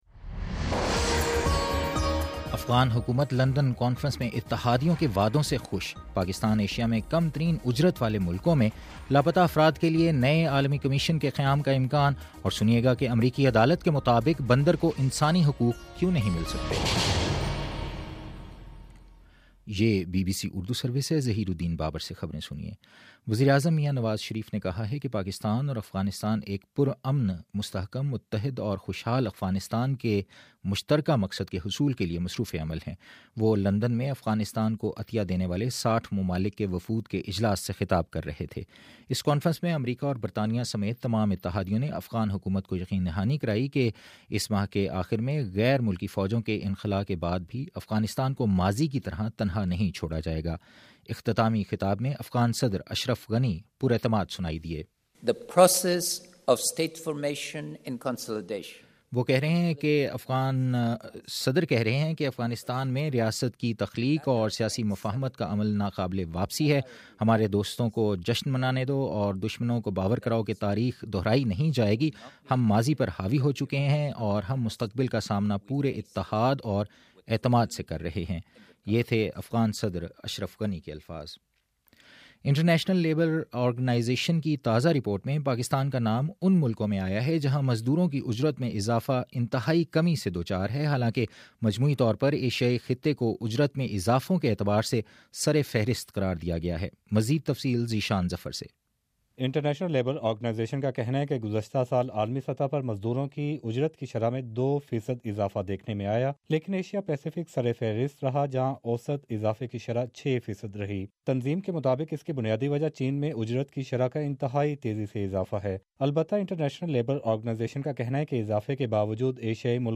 دسمبر05: : صبح نو بجے کا نیوز بُلیٹن